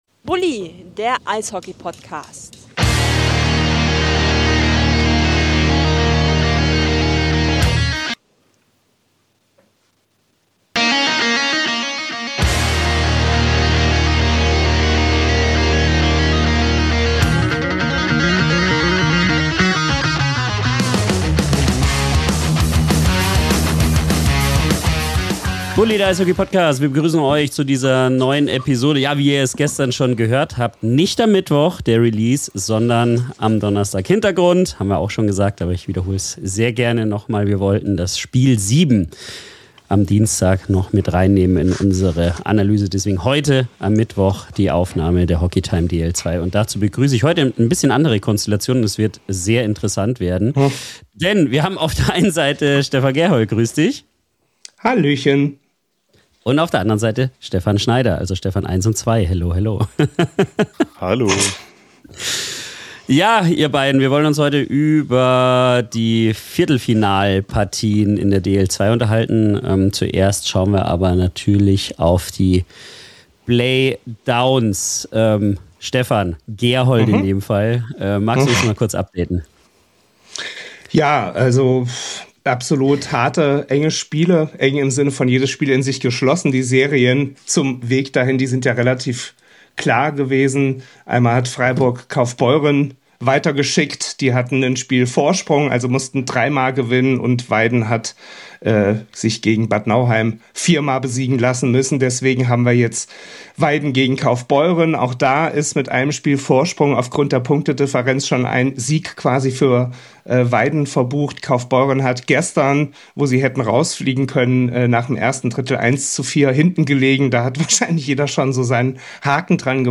Mit fundierten Einschätzungen, taktischen Analysen und persönlichen Eindrücken diskutieren wir mögliche Entwicklungen im Halbfinale und geben unsere Prognosen ab. Auch Themen wie Teamdynamik, Trainerentscheidungen und psychologische Aspekte kommen nicht zu kurz, während humorvolle Einwürfe und kleine Wetten für eine lockere Atmosphäre sorgen.